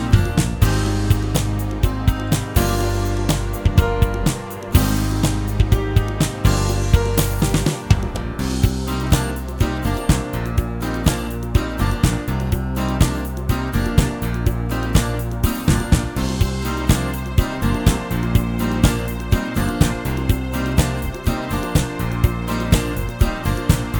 Minus All Guitars Soft Rock 3:12 Buy £1.50